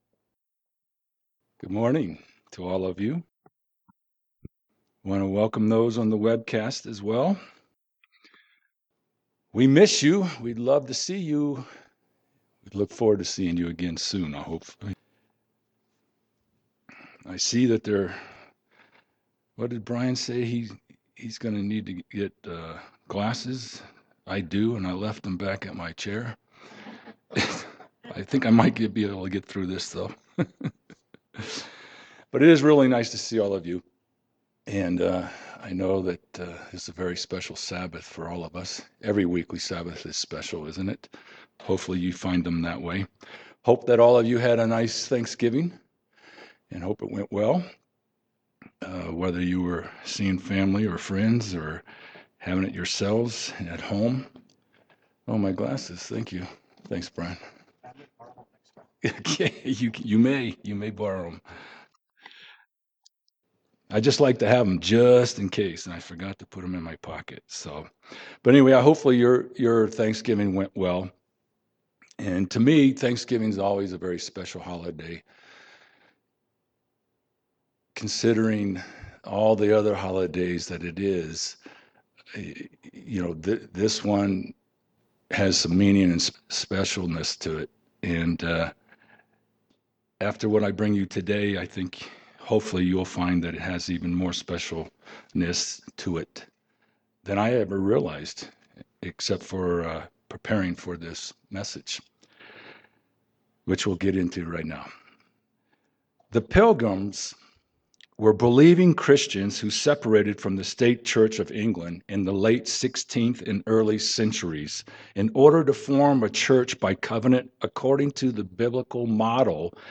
Sermons
Given in Denver, CO